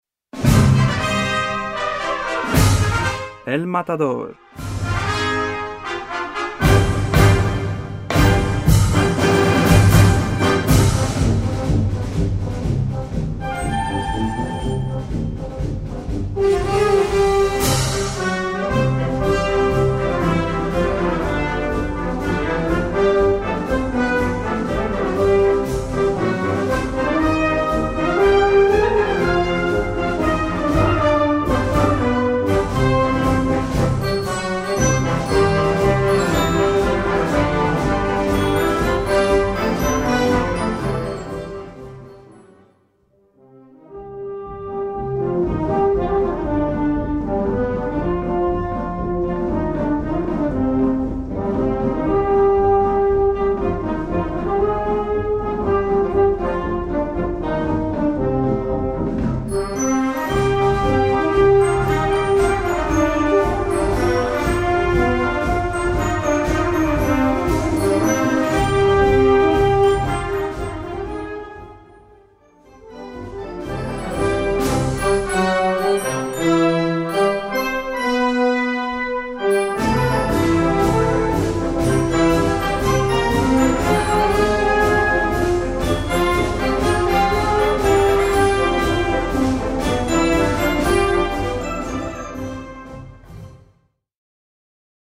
Besetzung: Blasorchester
Schönes Spanien, du riechst nach Ferien und Paso Doble!